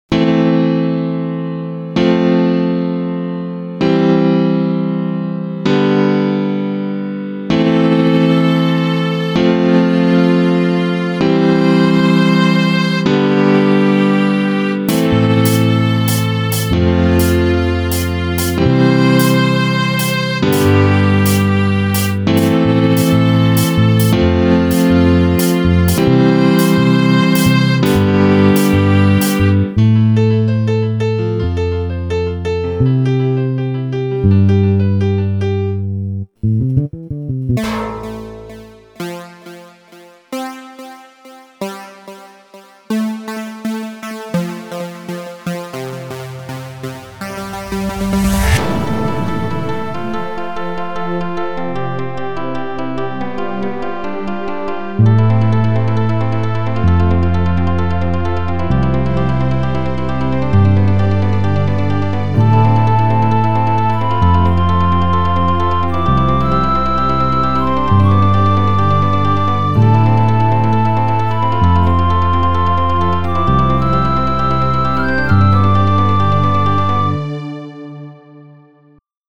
ביצוע פסנתר